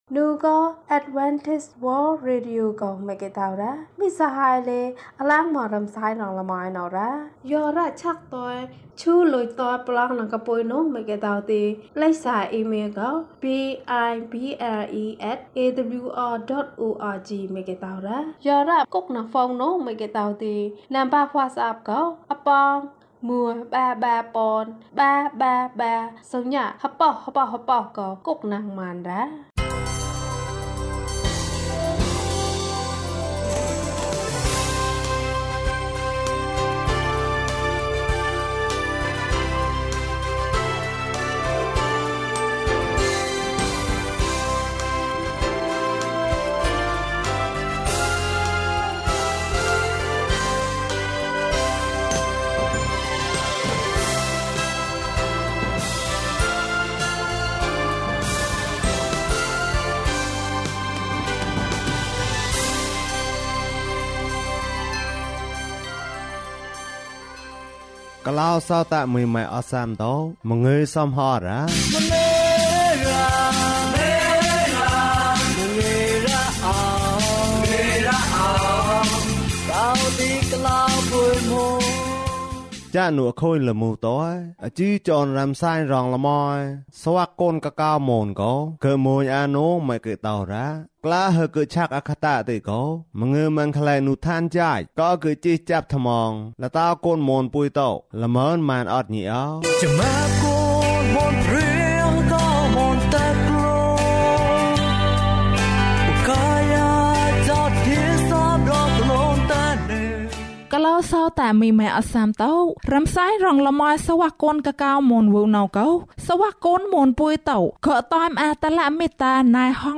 ငါယေရှုနဲ့လိုက်မယ်။ ကျန်းမာခြင်းအကြောင်းအရာ။ ဓမ္မသီချင်း။ တရားဒေသနာ။